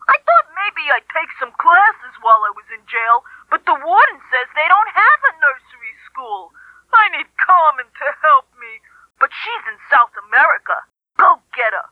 plus Eartha Brute's portrait and jail calls. 2025-11-17 05:14:43 -05:00 1.1 MiB Raw History